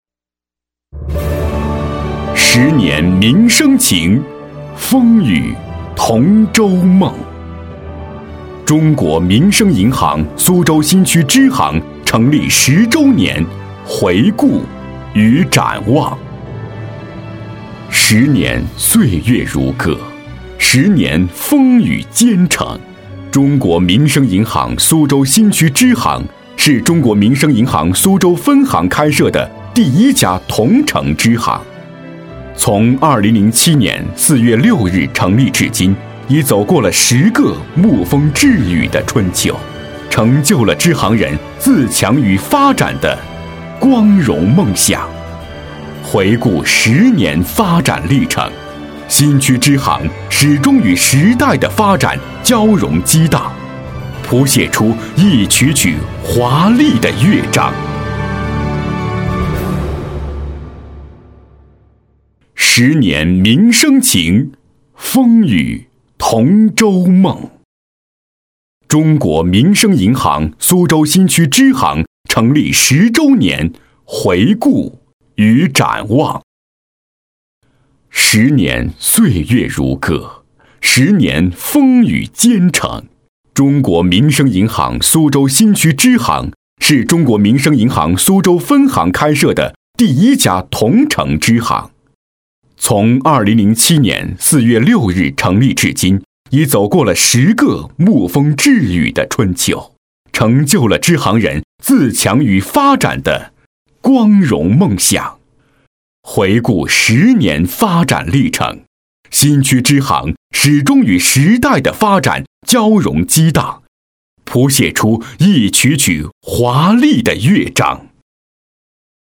男国475_专题_党建_党建_大气
标签： 大气
配音风格： 大气 讲述 浑厚 活力 年轻